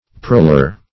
proller - definition of proller - synonyms, pronunciation, spelling from Free Dictionary Search Result for " proller" : The Collaborative International Dictionary of English v.0.48: Proller \Proll"er\, n. Prowler; thief.